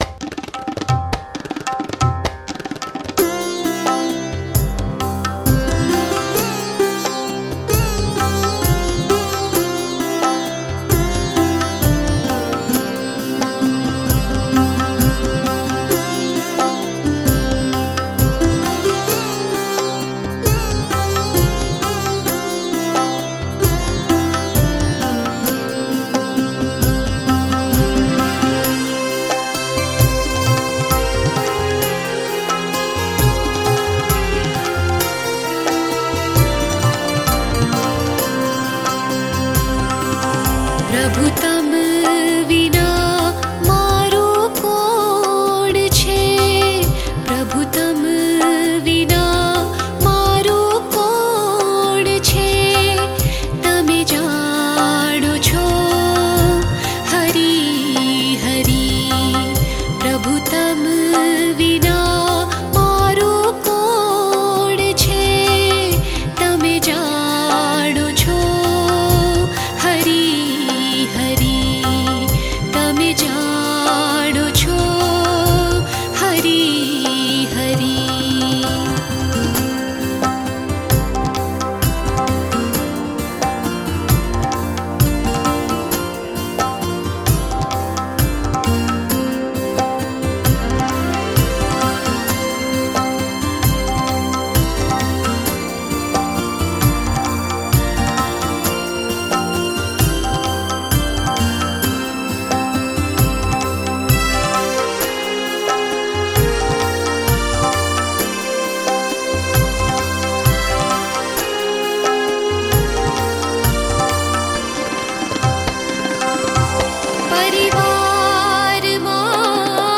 🎵 Prabhu Tam Vinā / પ્રભુ તમ વિના – રાગ : ગરબી